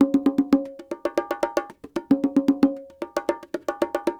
44 Bongo 03.wav